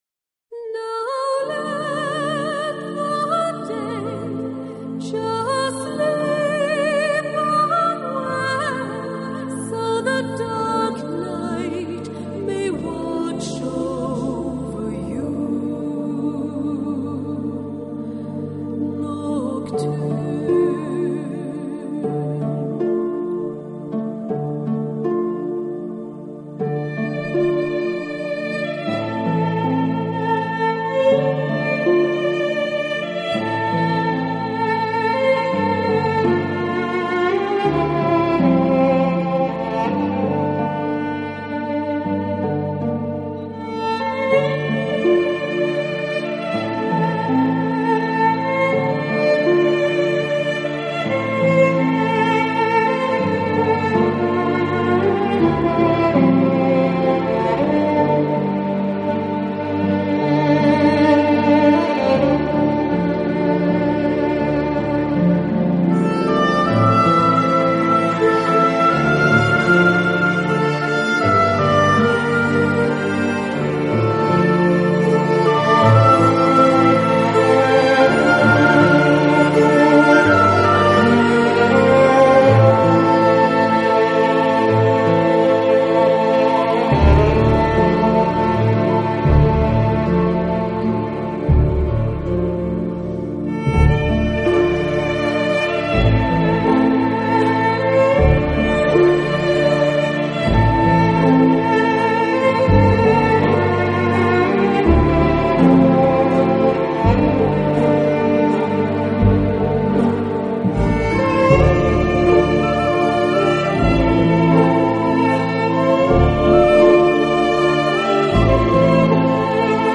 【凯尔特音乐】
genre: New Age